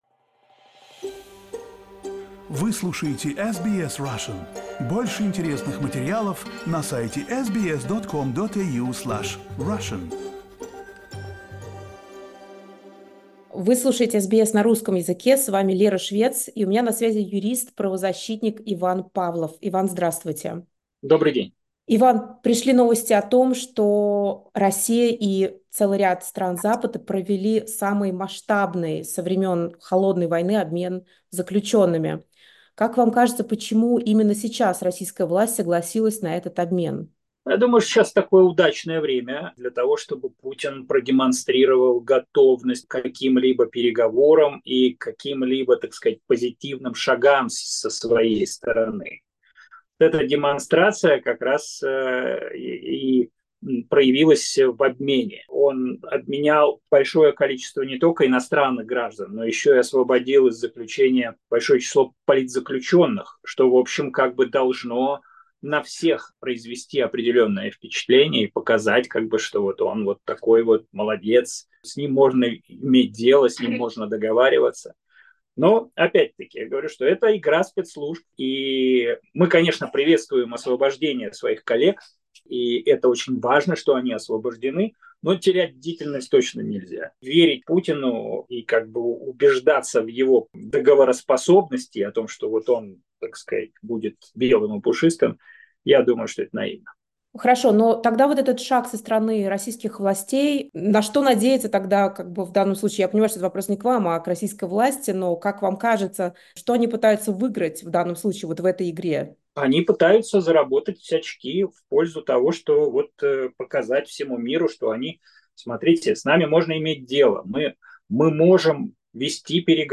прокомментировал эту новость в разговоре с SBS Russian.